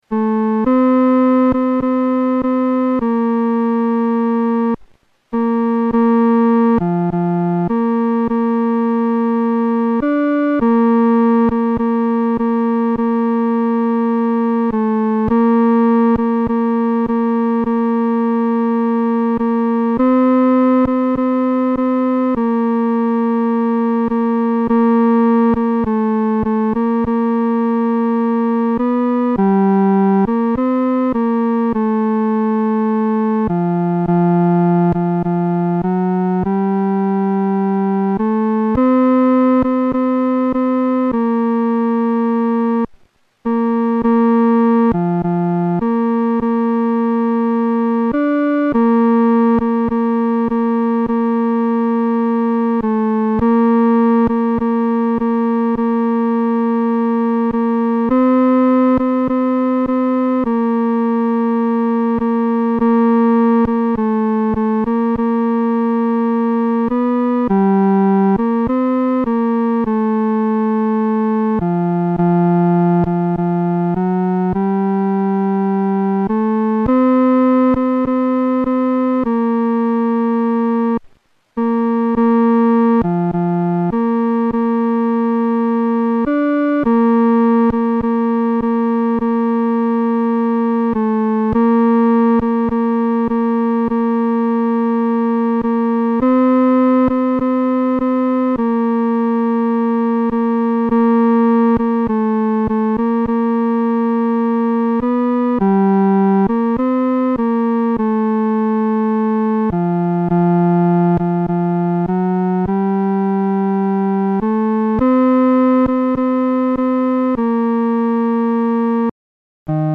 伴奏
男高